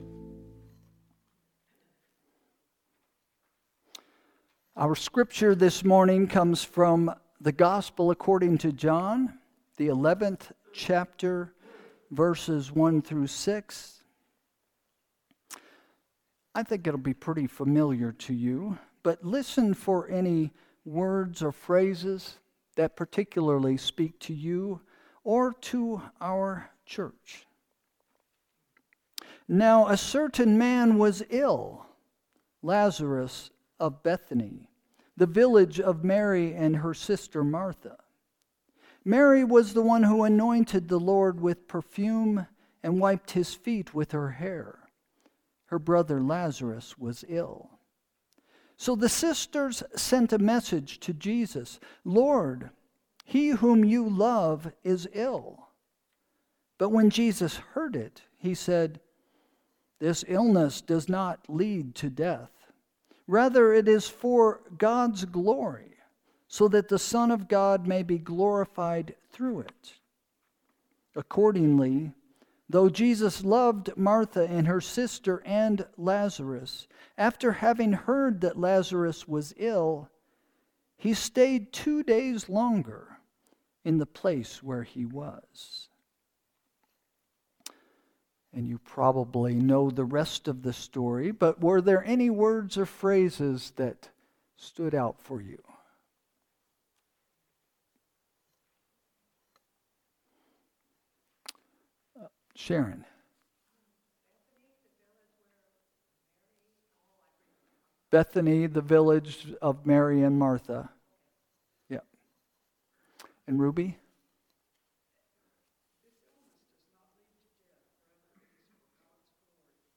Sermon – March 2, 2025 – “It’s About Time!”